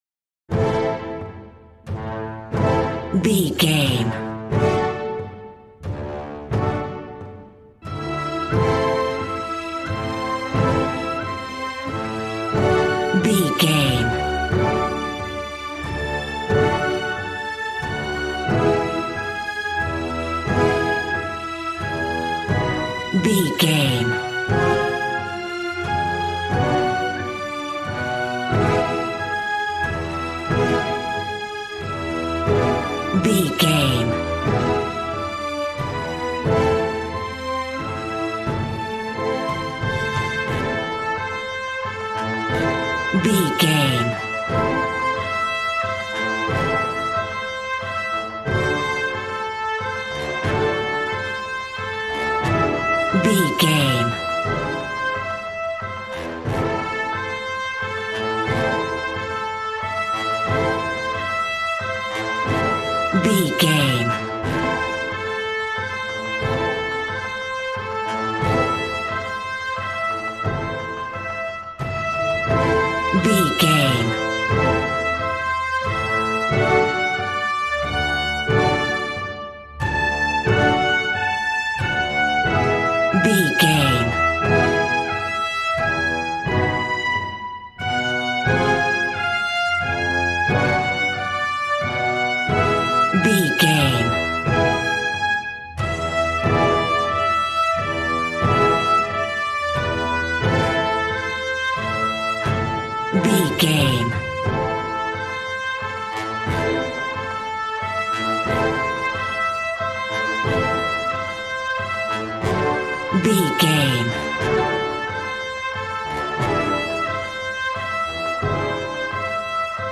Valiant and Triumphant music for Knights and Vikings.
Regal and romantic, a classy piece of classical music.
Ionian/Major
brass
strings
violin
regal